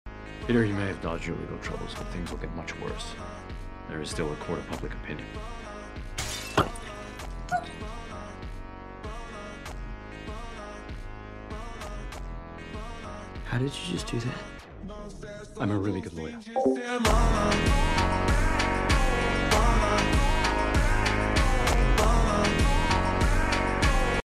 Ultra Slowed